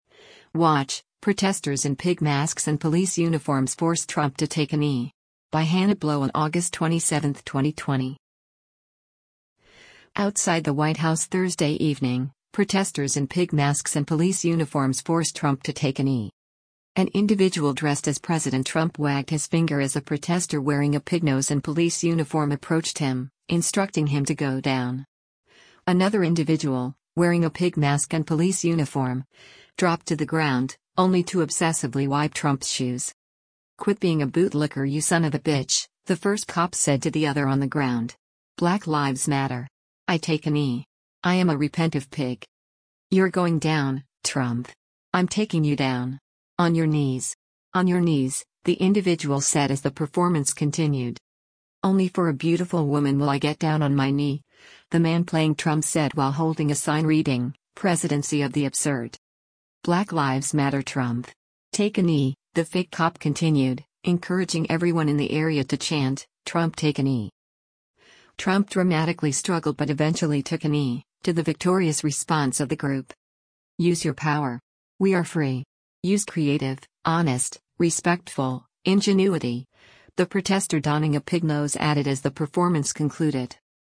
Outside the White House Thursday evening, protesters in pig masks and police uniforms forced “Trump” to take a knee.
“Black Lives Matter Trump. Take a knee,” the fake cop continued, encouraging everyone in the area to chant, “Trump take a knee.”
“Trump” dramatically struggled but eventually took a knee, to the victorious response of the group.